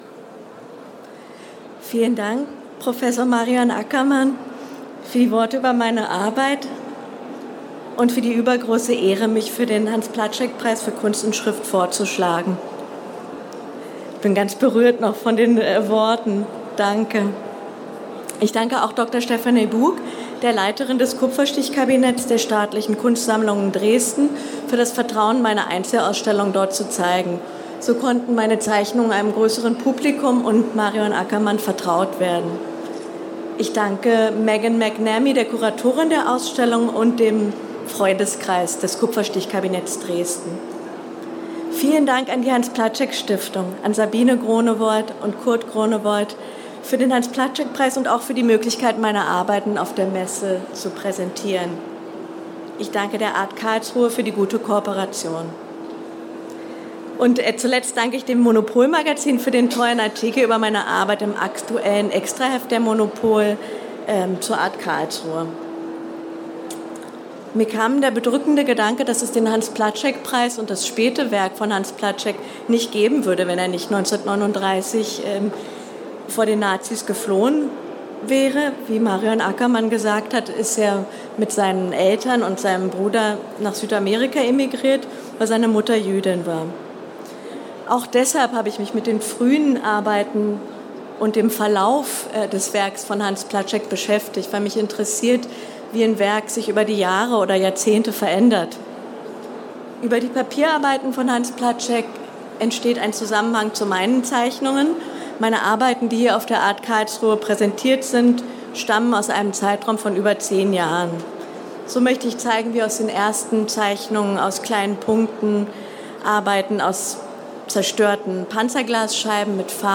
Reden zur Preisverleihung